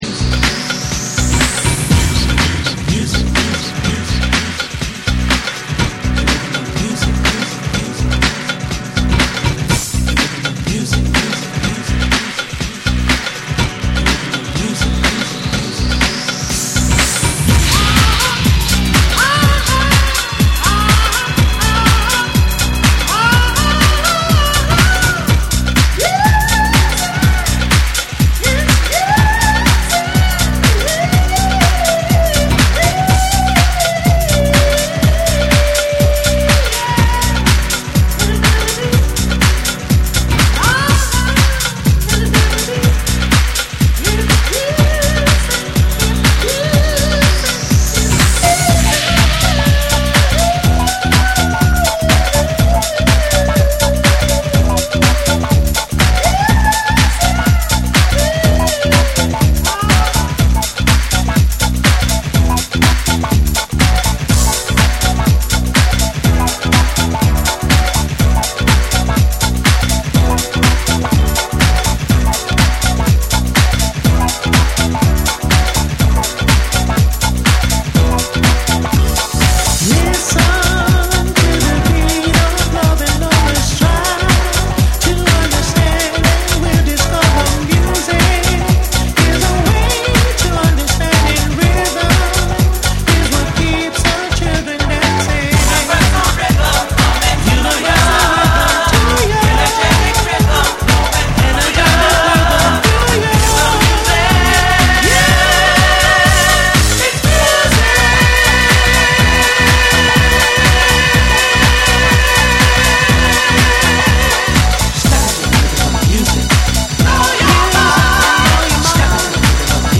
ソウルフルなヴォーカル・ハウス
躍動感あふれるビートに伸びやかな歌声が映える、90Sテイストの温かみある1曲。
TECHNO & HOUSE / DISCO DUB